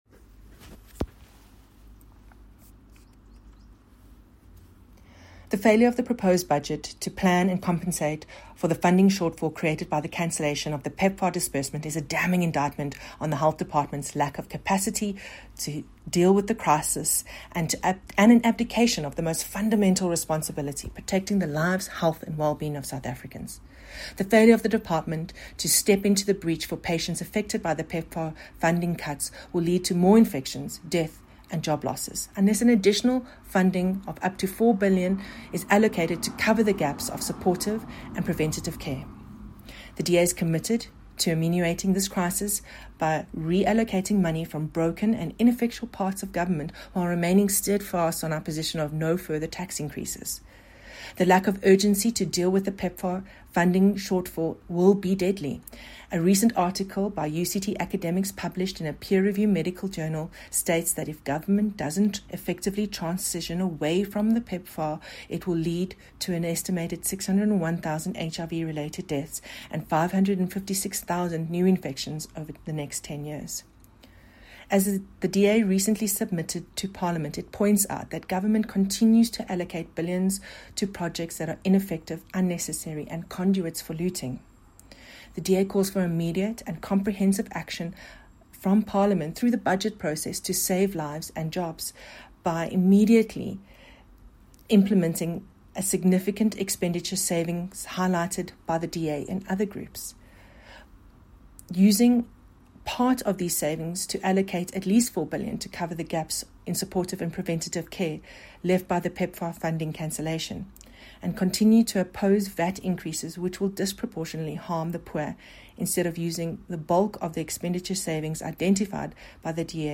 soundbite by Wendy Alexander MP